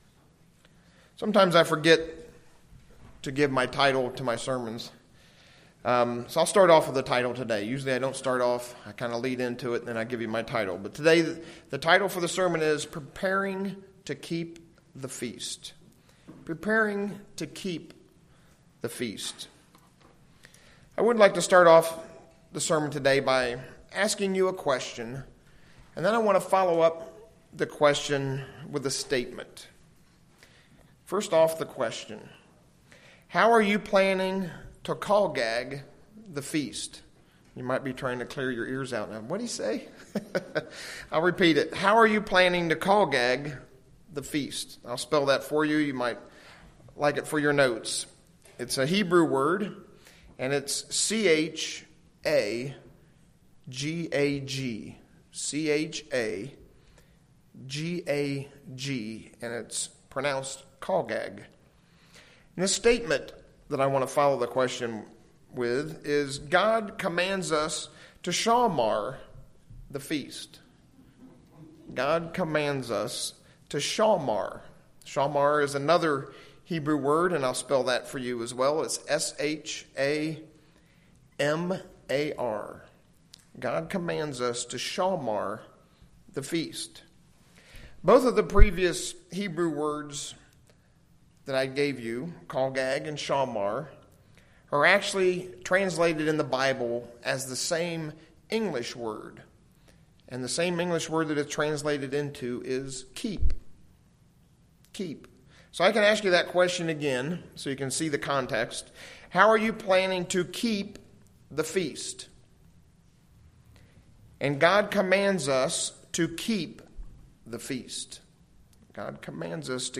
This sermon takes a deeper look at the word "keep" in how we are keeping God's Feast.
Given in Ft. Wayne, IN